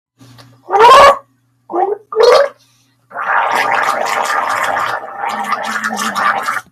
Gurgle Sound Button - Free Download & Play